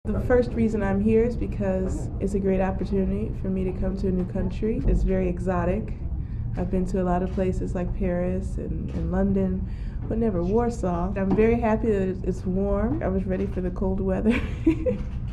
(RadioZet) Źródło: (RadioZet) Mówi Venus Williams Tłumaczenie: Po pierwsze jestem tu, bo to znakomita okazja by poznać nowy, egzotyczny dla mnie kraj.